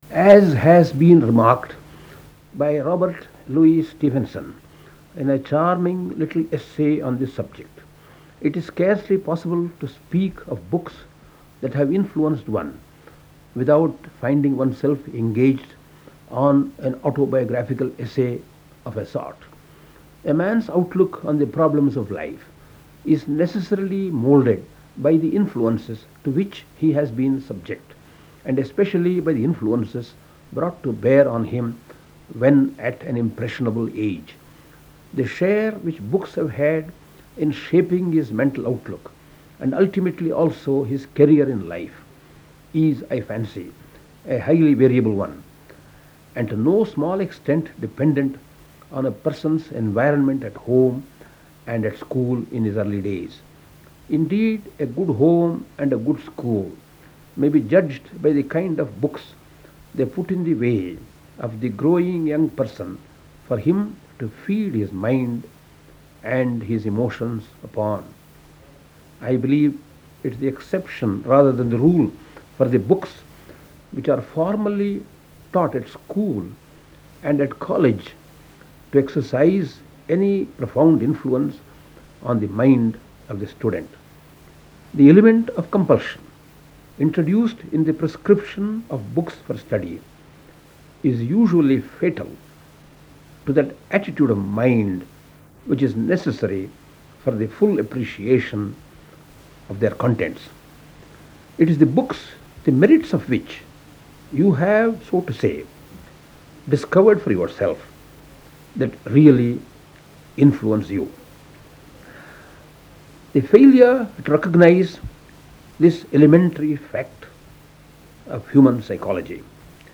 Books That Have Influenced Me -Talk C.V.Raman (Dr).WAV